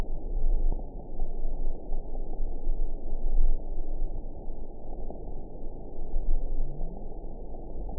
event 921578 date 12/04/24 time 21:00:02 GMT (6 months, 2 weeks ago) score 5.38 location TSS-AB07 detected by nrw target species NRW annotations +NRW Spectrogram: Frequency (kHz) vs. Time (s) audio not available .wav